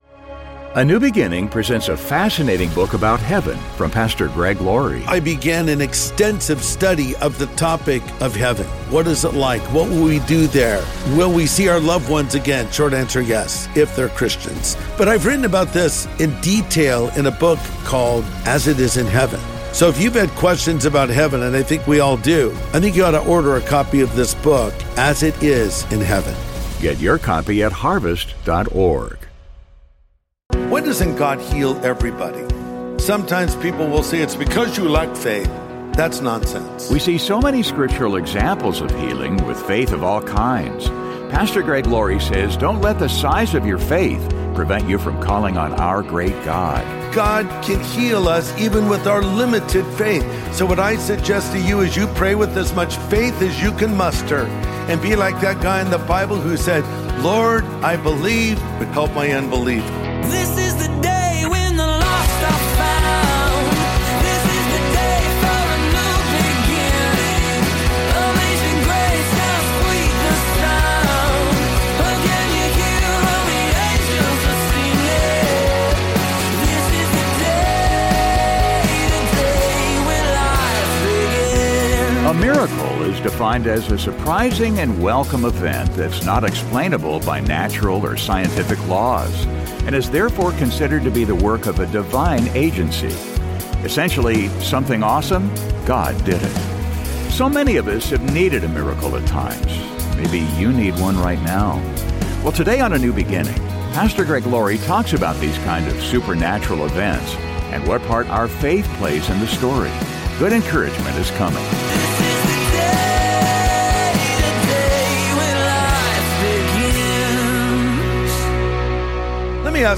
So many of us have needed a miracle at times . . . maybe you need one right now. Well today on A NEW BEGINNING, Pastor Greg Laurie talks about these kind of supernatural events, and what part our faith plays in the story.